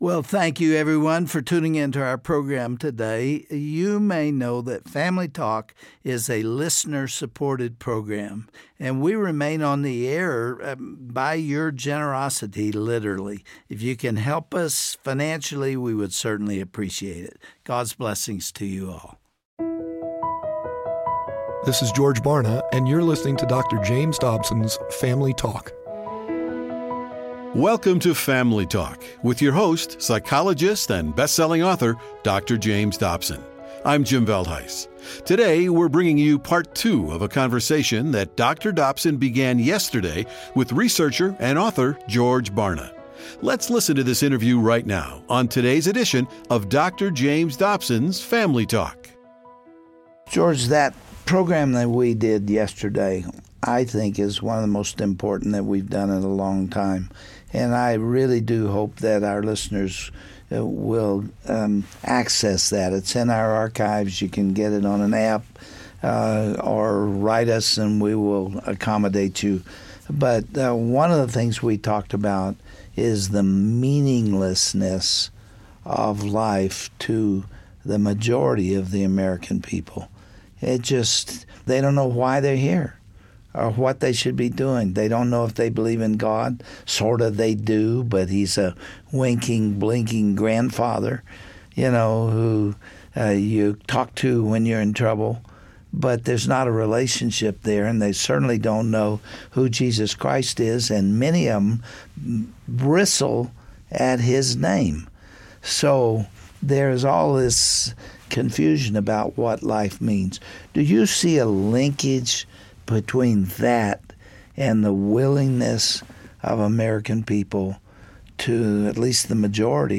Dr. Dobson concludes his conversation with respected author and researcher George Barna. On this broadcast, they will talk about the pervasiveness of postmodernism, and how its perverting the minds of young people in todays culture, and the role parents and even grandparents have in raising the next generation.